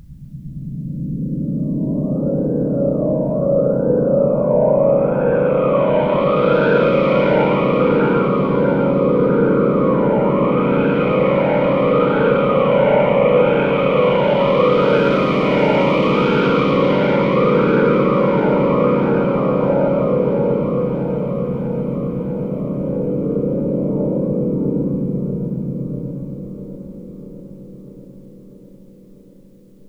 dischord.wav